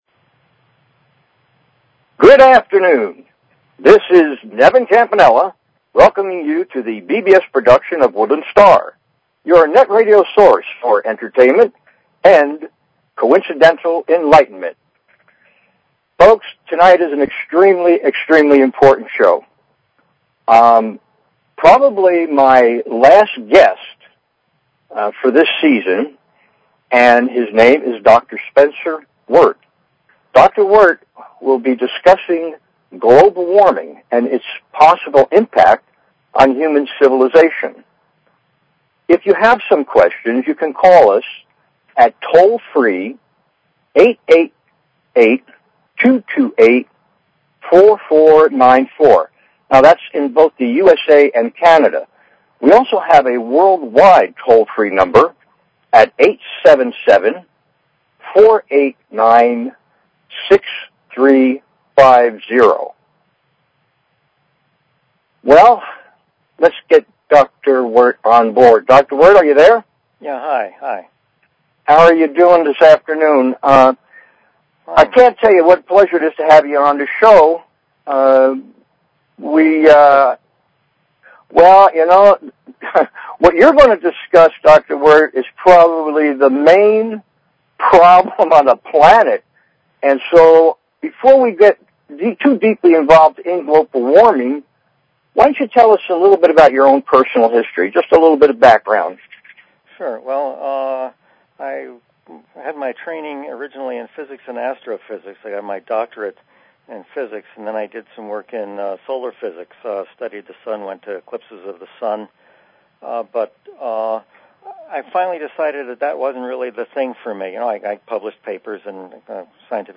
Talk Show Episode, Audio Podcast, Woodland_Stars_Radio and Courtesy of BBS Radio on , show guests , about , categorized as